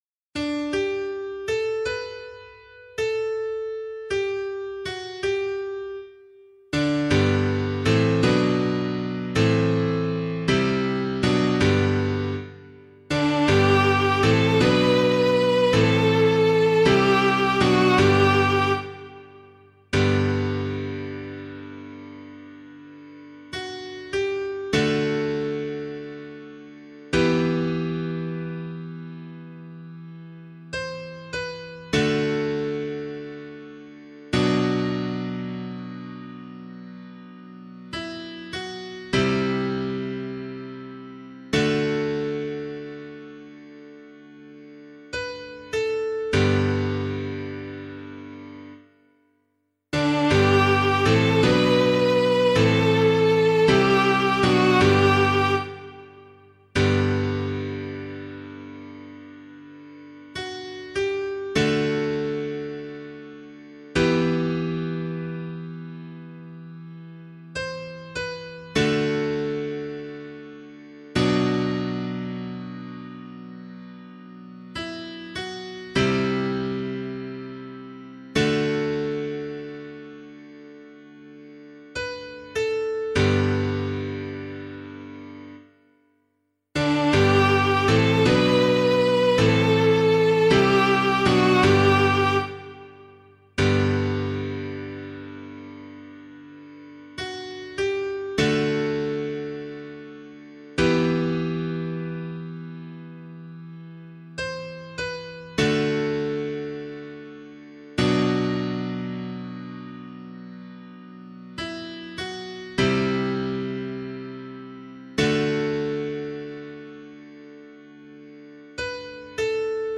003 Advent 3 Psalm B [LiturgyShare 7 - Oz] - piano.mp3